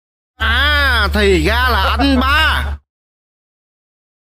Thể loại: Câu nói Viral Việt Nam
Description: Đây là một trong những âm thanh meme viral trên TikTok, khiến người nghe không thể nhịn cười.